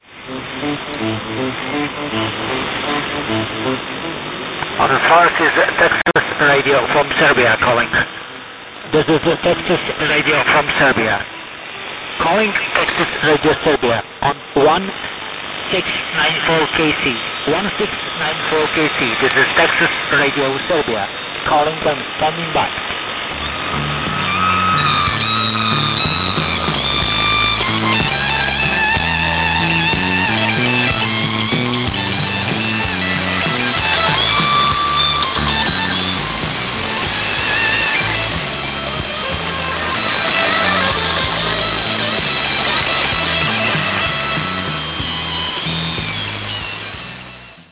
On Tuesday evening 30.12.2008 Radio Texas tested new PLL-transmitter with 80 Watts output (playing blues music). Signal was surprisingly good in Finland for some moments on 1694 kHz (21.25 utc).